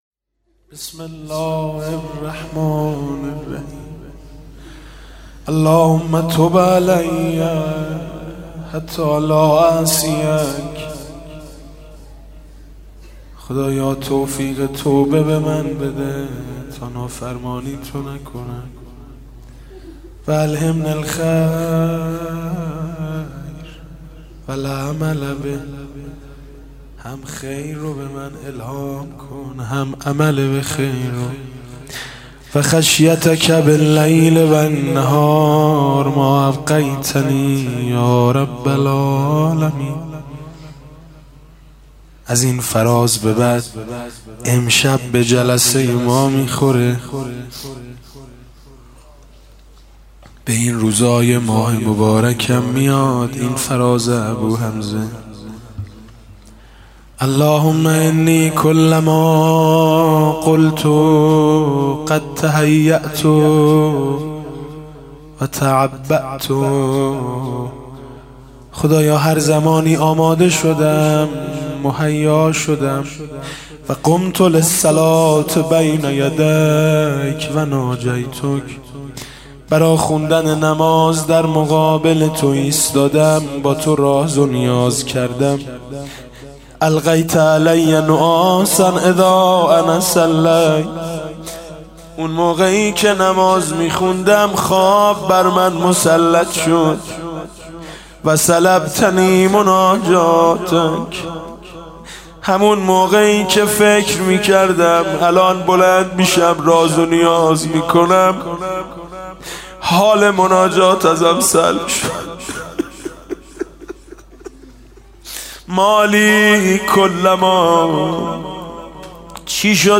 شب چهاردهم رمضان 96 - هیئت شهدای گمنام - فرازهایی از دعای ابوحمزه ثمالی